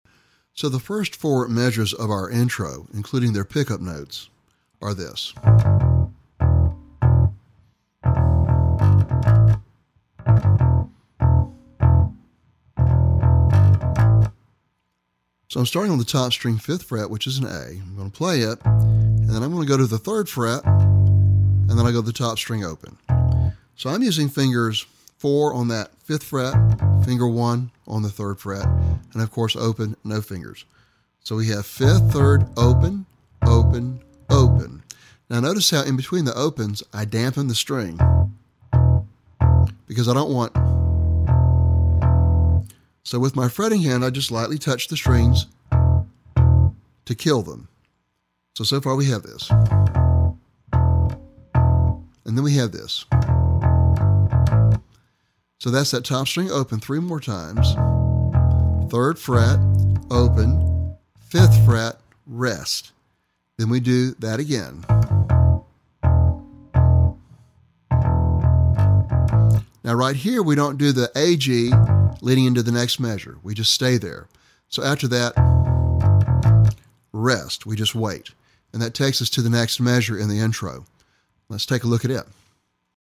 (Bass Guitar) CD
Lesson Sample
For Bass Guitar.